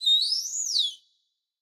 Minecraft Version Minecraft Version latest Latest Release | Latest Snapshot latest / assets / minecraft / sounds / mob / dolphin / idle_water1.ogg Compare With Compare With Latest Release | Latest Snapshot
idle_water1.ogg